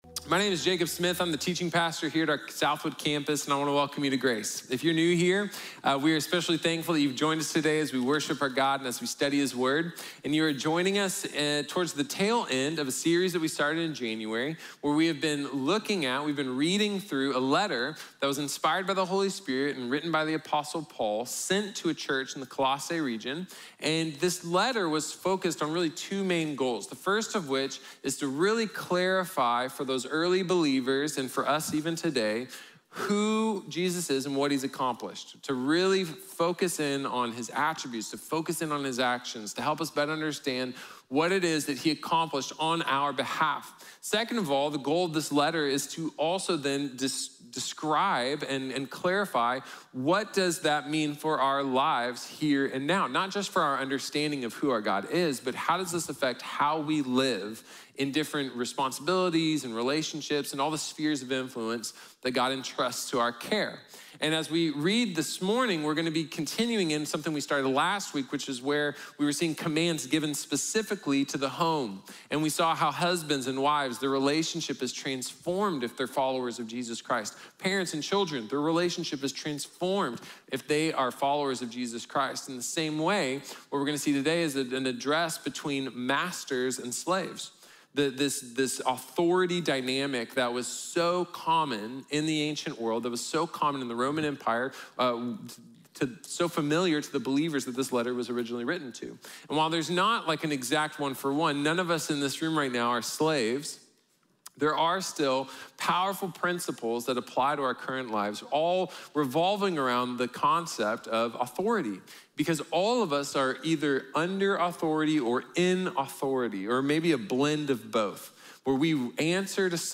Autoridad Completa | Sermón | Iglesia Bíblica de la Gracia